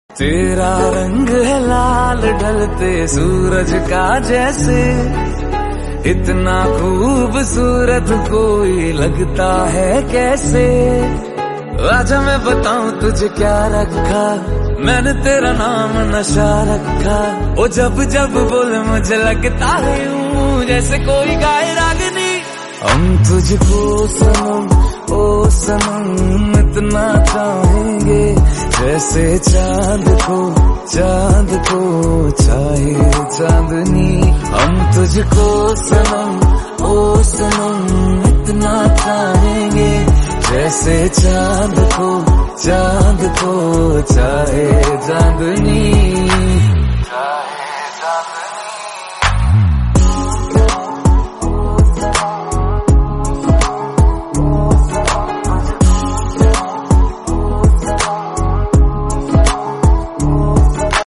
sand writing